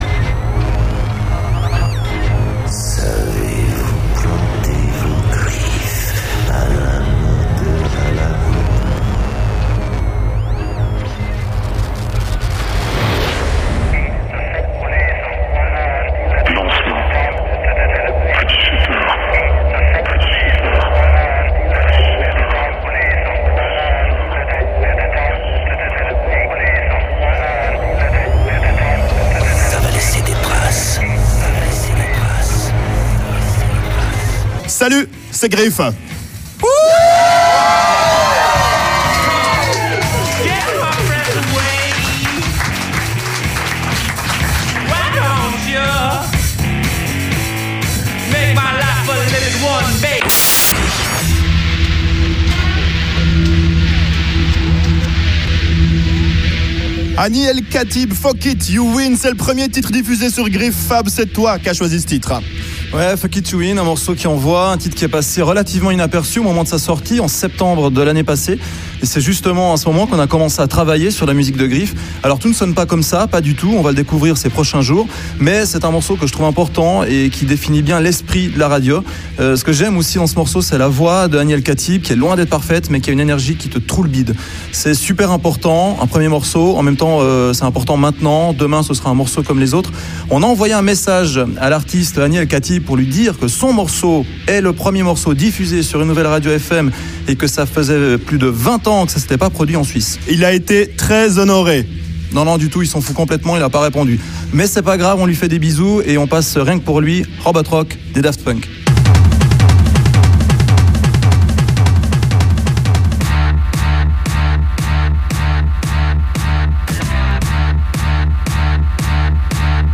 Voici un résumé des 20 premières minutes: